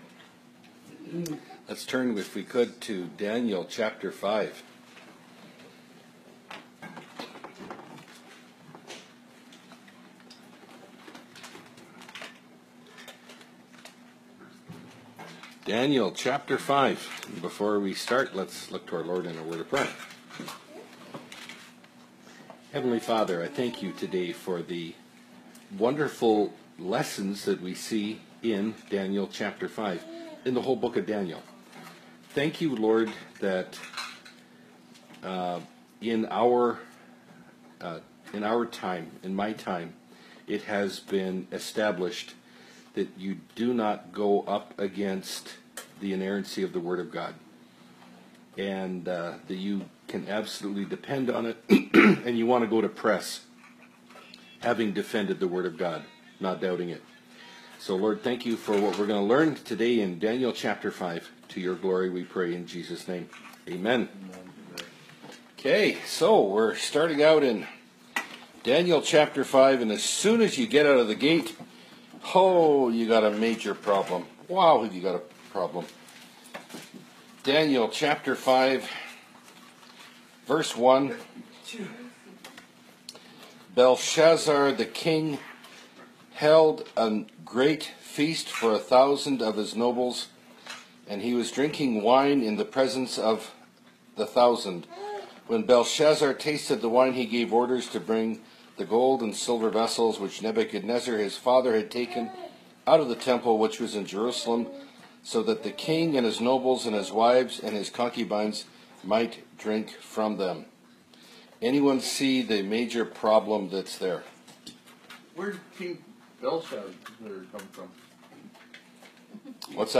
Bible Study – Daniel 5 – (2017)